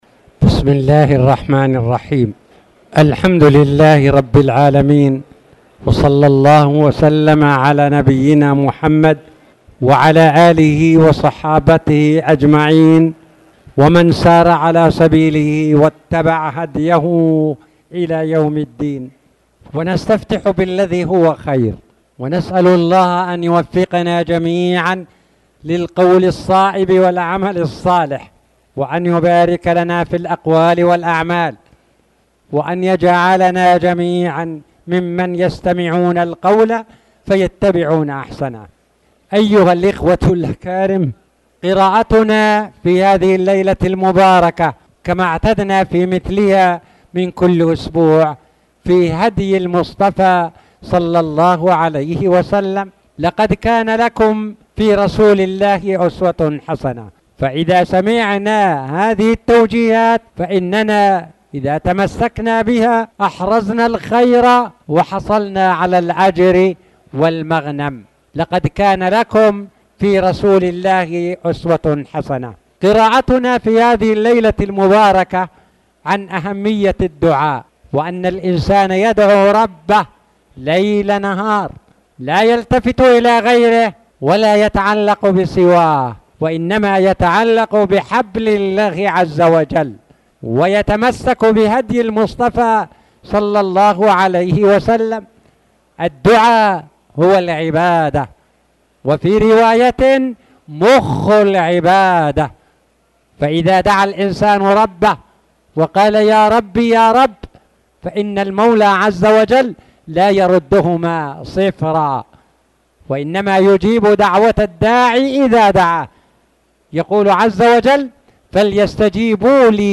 تاريخ النشر ٣ جمادى الآخرة ١٤٣٨ هـ المكان: المسجد الحرام الشيخ